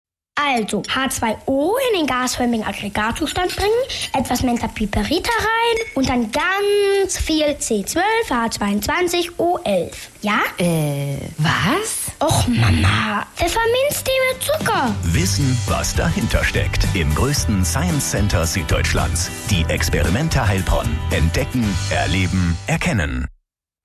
Werbespot auf: (mehr …)